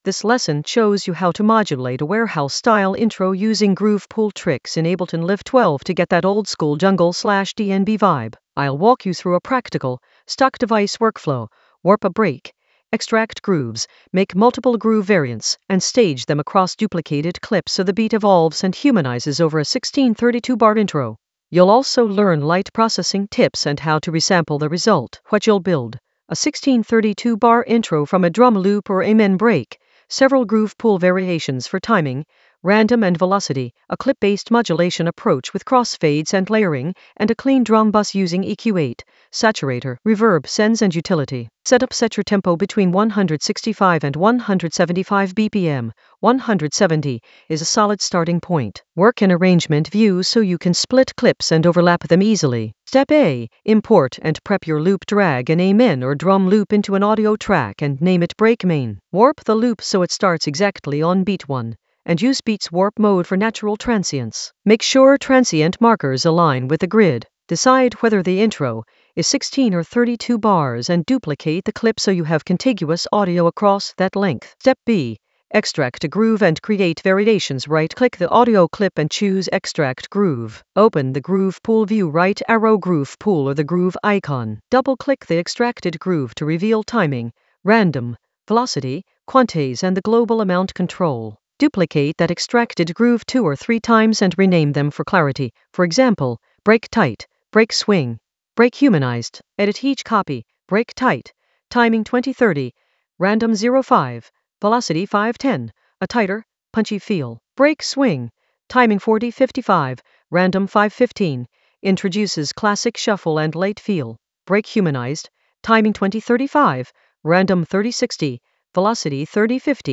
An AI-generated beginner Ableton lesson focused on Modulate a warehouse intro using groove pool tricks in Ableton Live 12 for jungle oldskool DnB vibes in the Drums area of drum and bass production.
Narrated lesson audio
The voice track includes the tutorial plus extra teacher commentary.